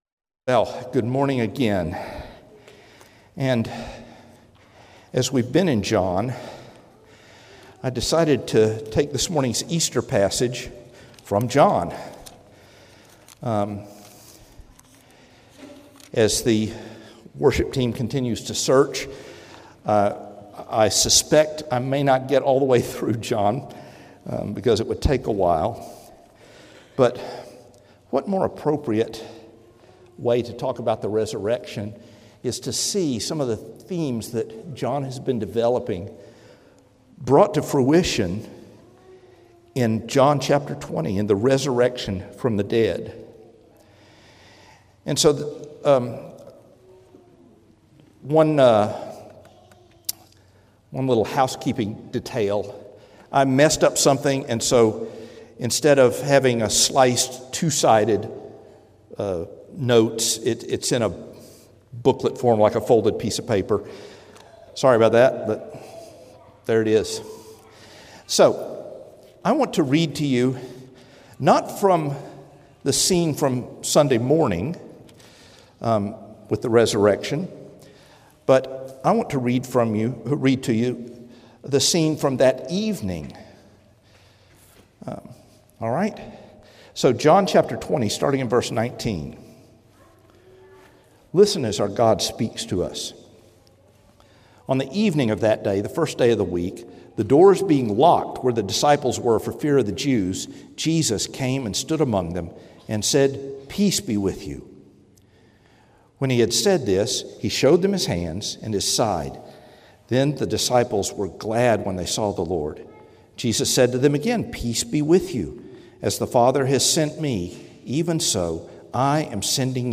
Sermons – Trinity Presbyterian Church